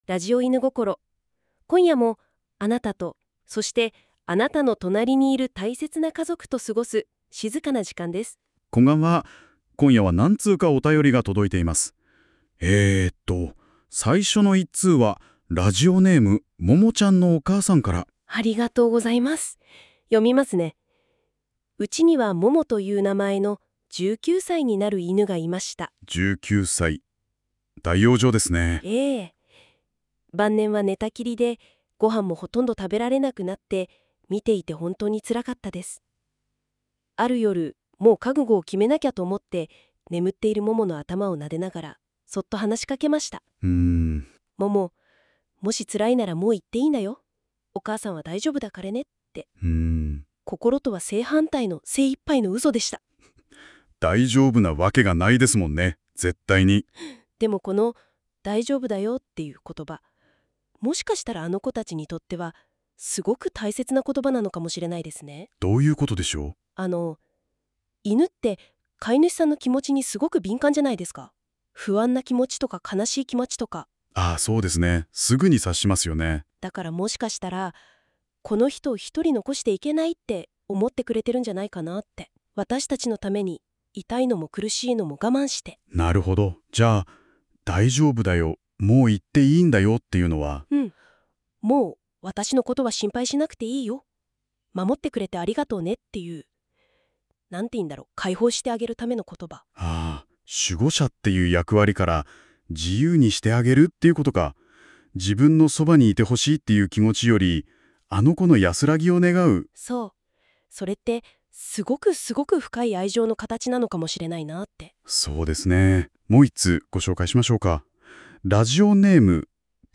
心安らぐ、夜のラジオ。
まだ見習い中のAIパーソナリティなので少しイントネーションが不自然だったり、悲しい話なのに声が弾んでしまうことがあるかもしれません。